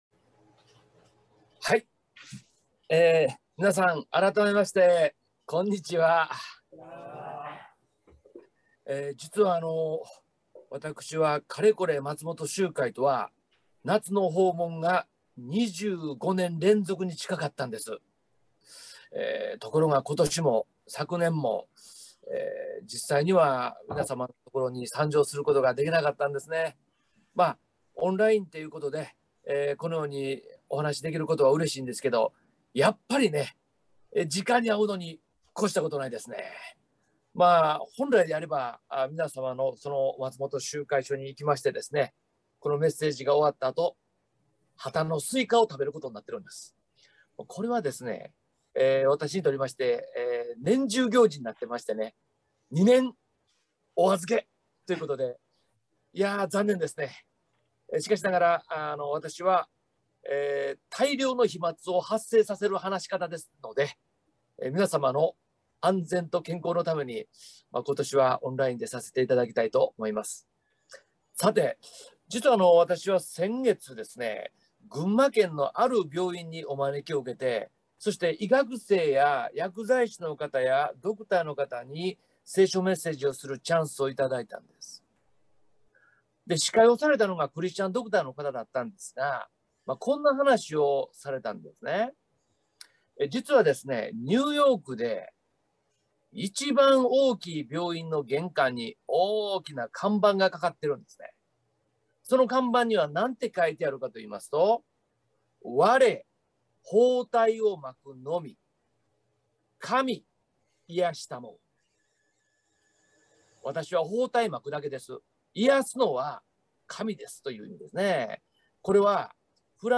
聖書メッセージ